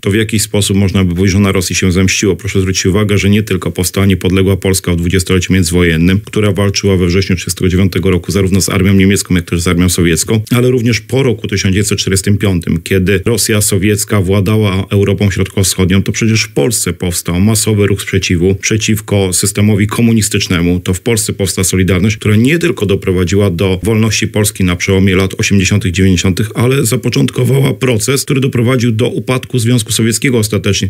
– Rosjanie nie doceniali Polaków, nie doceniali Polski przez cały wiek XIX ani w wieku XX – mówi Radiu Lublin historyk, dyrektor Instytutu Pamięci Narodowej w Lublinie, doktor Robert Derewenda.